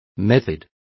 Also find out how procedimiento is pronounced correctly.